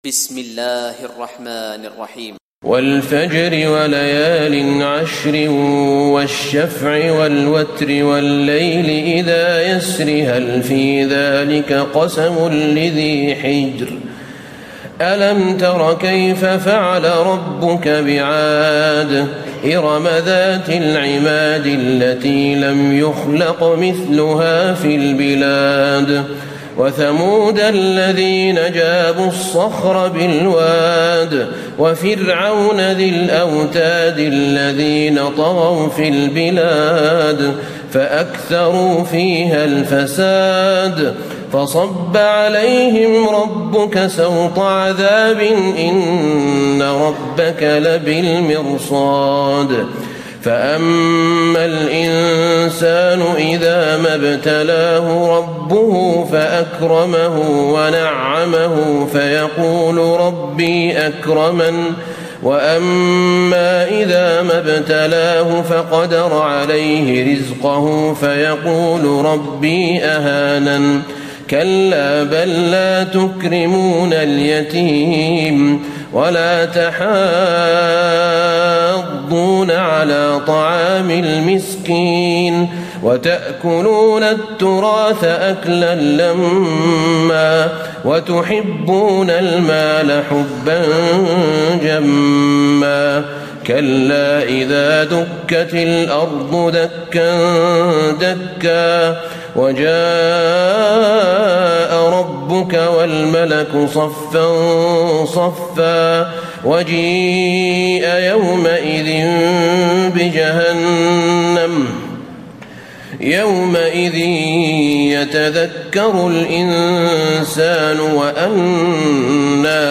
تراويح ليلة 29 رمضان 1436هـ من سورة الفجر الى البينة Taraweeh 29 st night Ramadan 1436H from Surah Al-Fajr to Al-Bayyina > تراويح الحرم النبوي عام 1436 🕌 > التراويح - تلاوات الحرمين